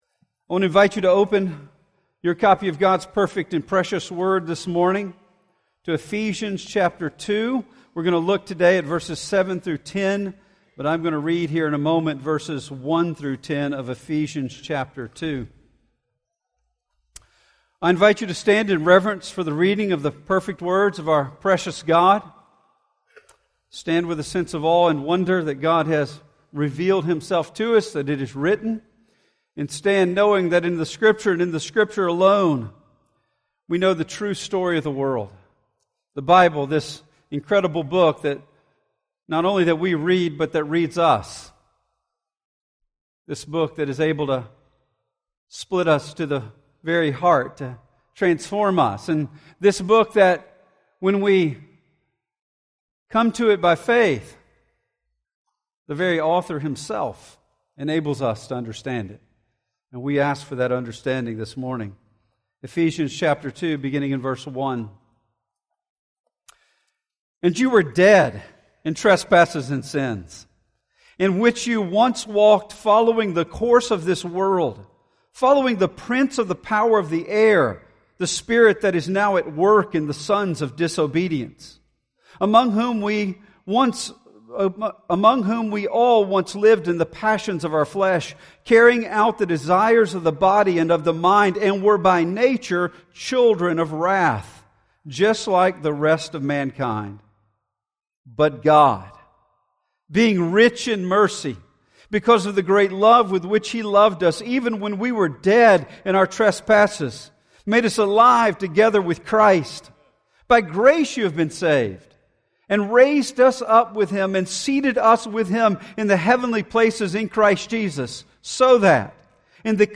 In this sermon from our Ephesians series we see the overwhelming truth of God sovereign grace in making the church His masterpiece.